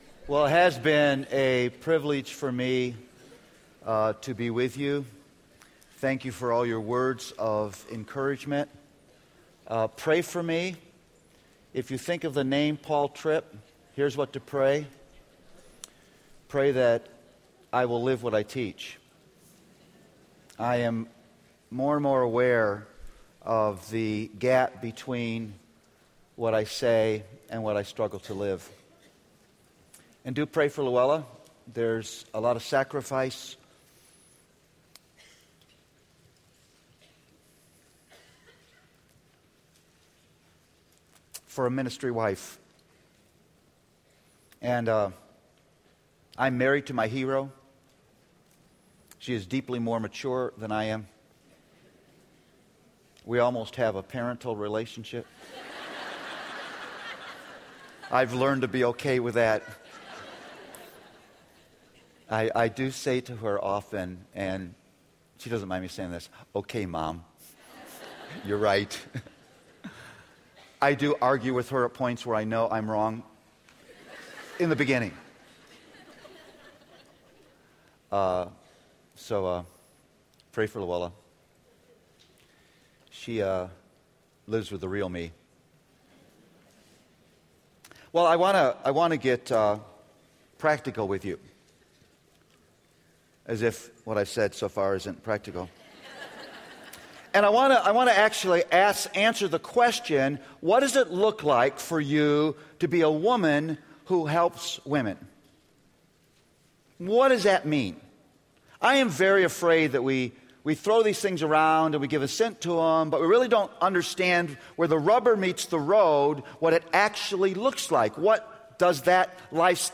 R13-Plenary-Session5-PDT.mp3